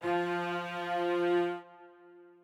strings9_13.ogg